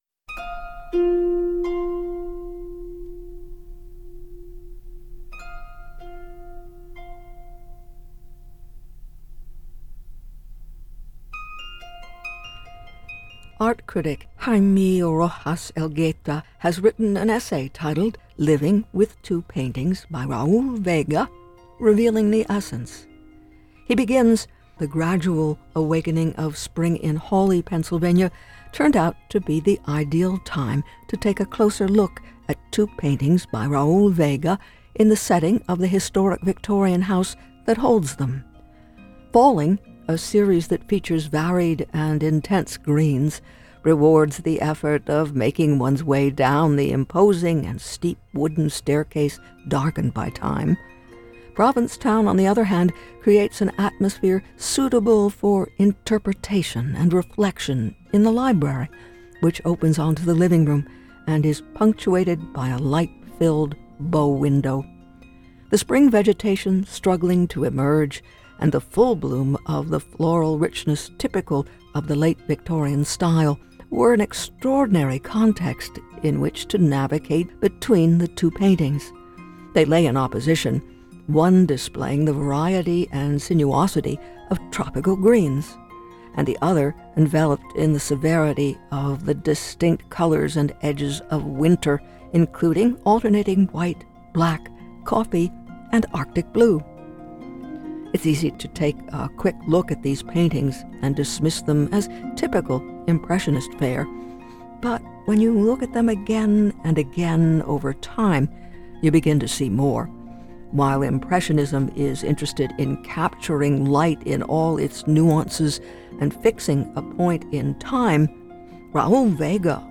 Join her weekdays at Noon for interviews, reviews and commentaries on films, books, jazz, and classical music.